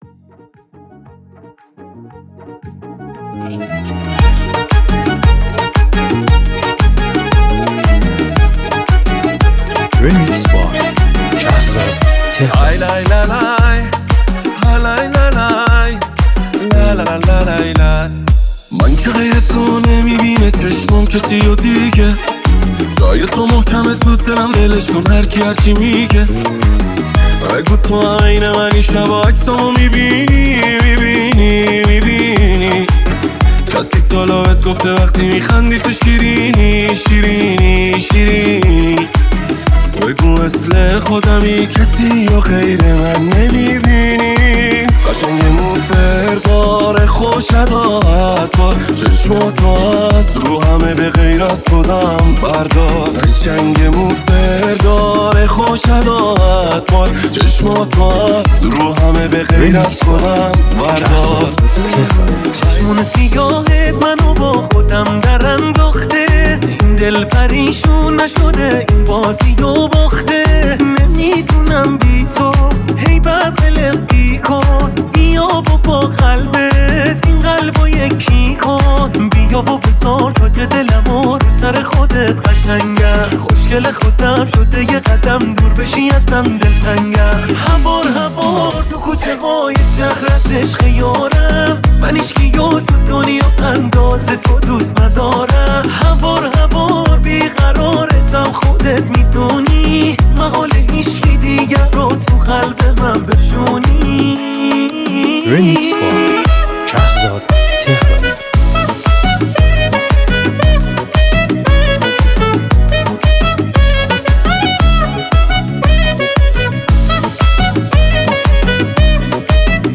بهترین آهنگ‌های عاشقانه شاد ایرانی
ریمیکس